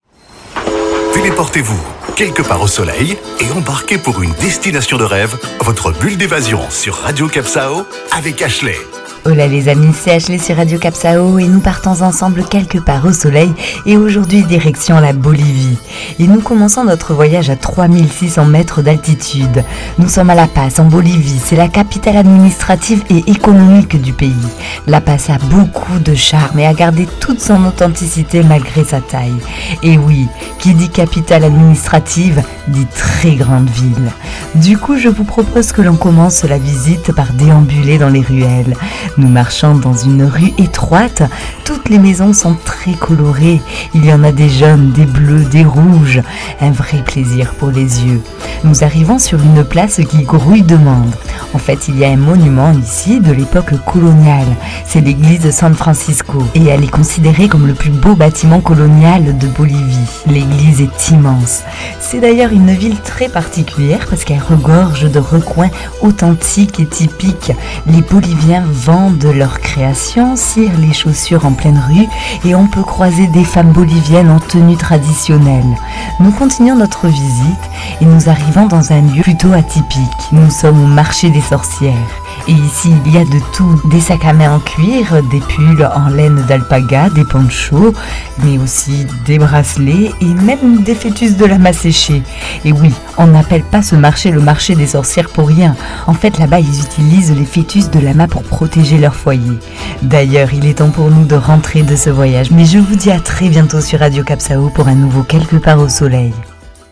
Carte postale sonore : respirez, nous sommes perchés à 3600m d'altitude, sur la plaine de l’Altiplano, surplombée par le mont Illimani enneigé en arrière-plan.